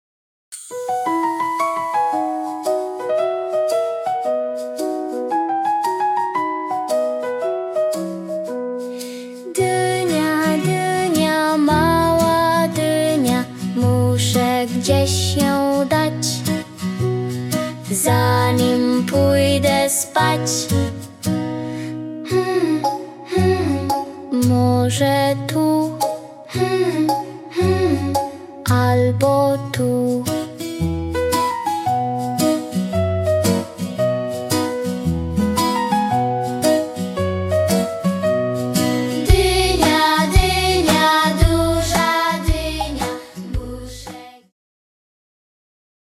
prosta, rytmiczna piosenka dla dzieci w wieku 2–3 lat
• nagranie (wersja wokalna i podkład muzyczny),